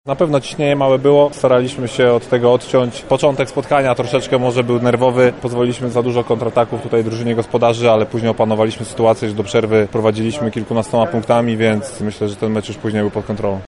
– Kontrolowaliśmy przebieg tego meczu – wyjaśnia skrzydłowy Trefla